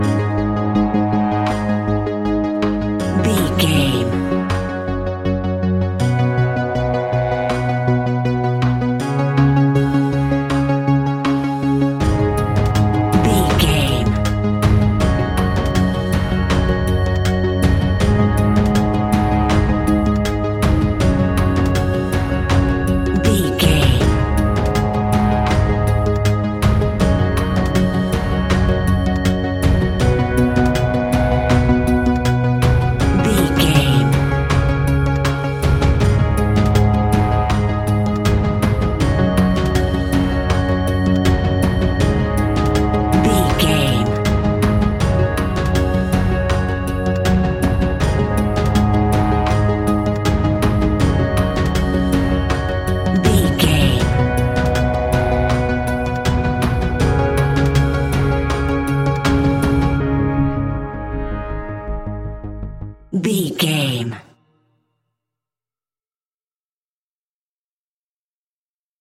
Aeolian/Minor
A♭
ominous
dark
haunting
eerie
synthesizer
drum machine
horror music
Horror Pads